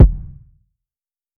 TC2 Kicks2.wav